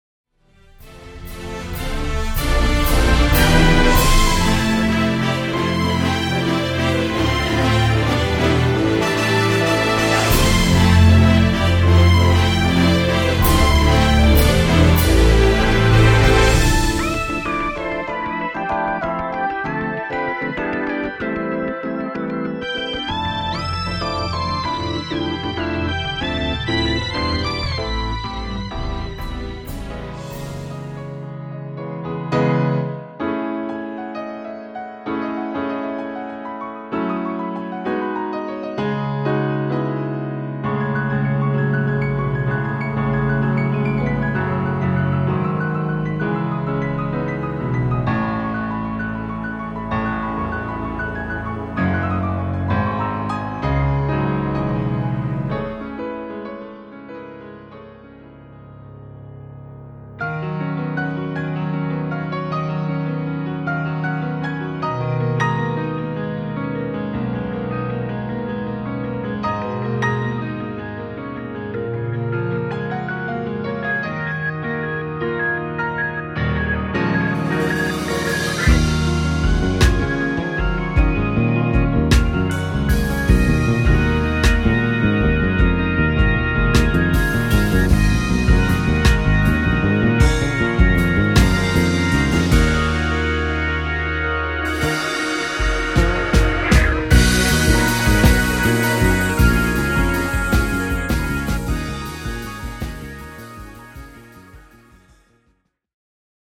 シンフォニックで粒揃いなミニアルバムです。（CD-R盤）